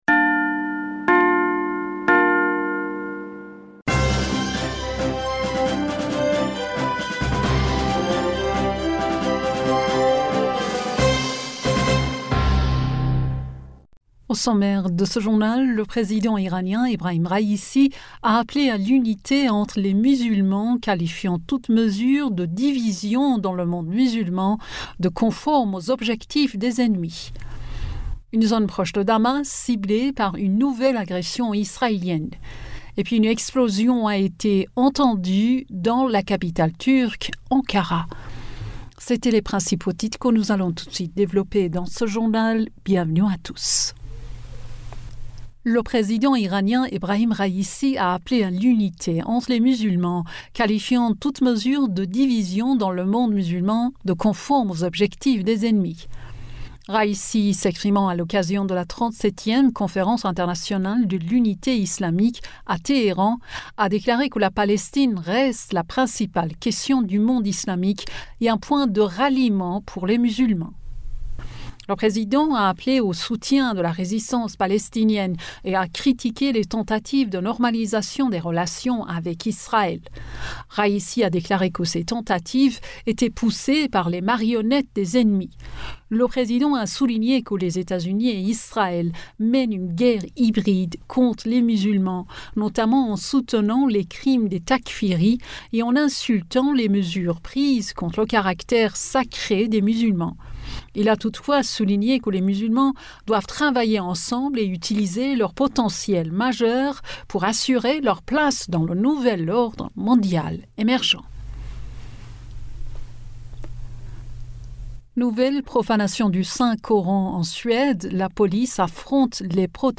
Bulletin d'information du 01 Octobre 2023